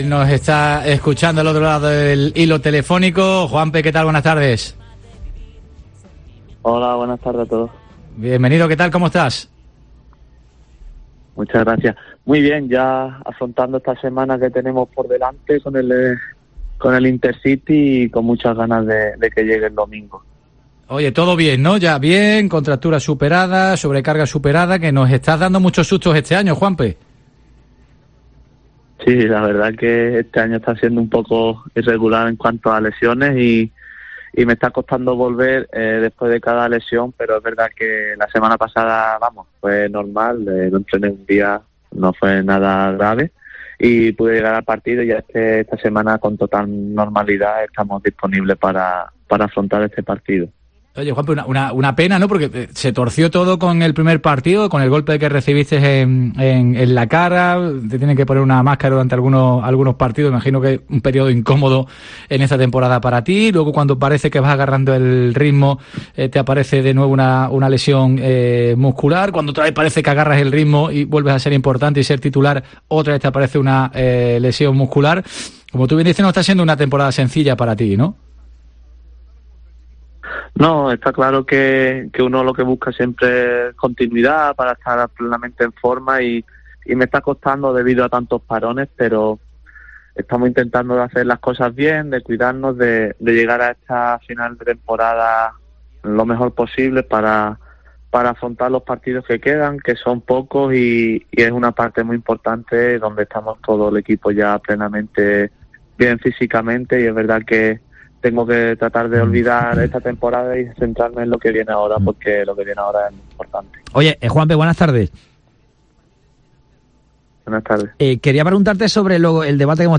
El jugador del Málaga habla sobre su temporada y las opciones de ascenso del Málaga